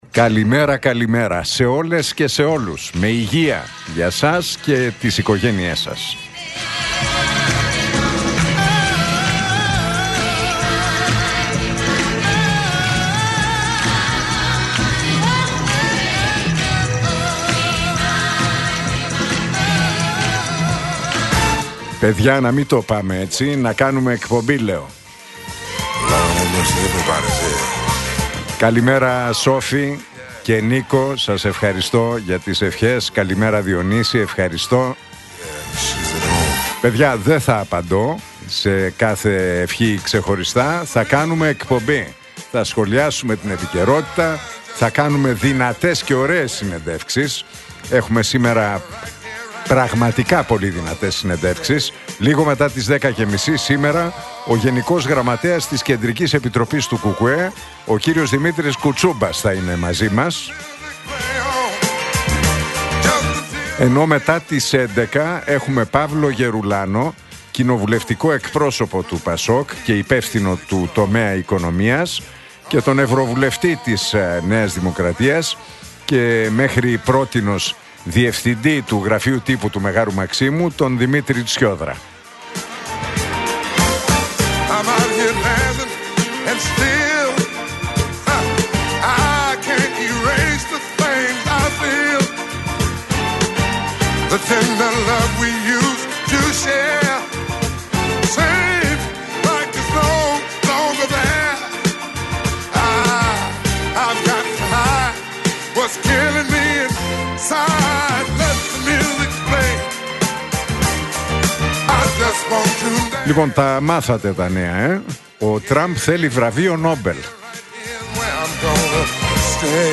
Ακούστε το σχόλιο του Νίκου Χατζηνικολάου στον ραδιοφωνικό σταθμό Realfm 97,8, την Πέμπτη 9 Οκτώβριου 2025.